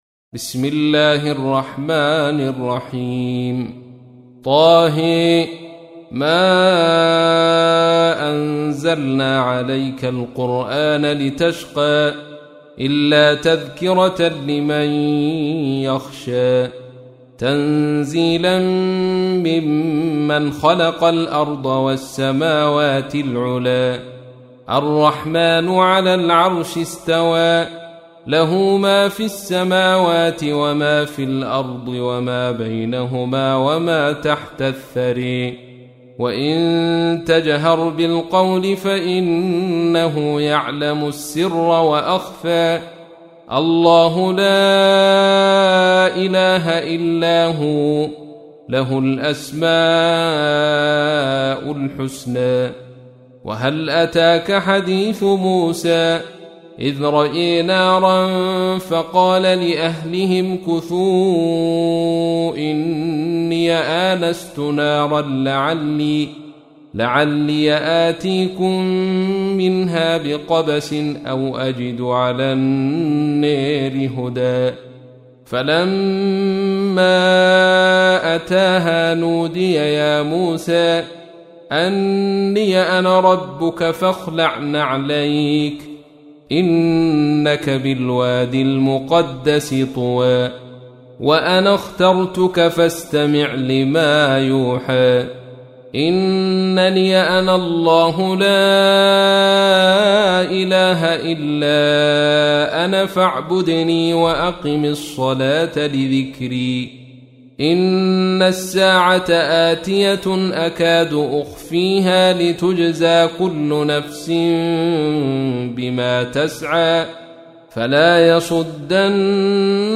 تحميل : 20. سورة طه / القارئ عبد الرشيد صوفي / القرآن الكريم / موقع يا حسين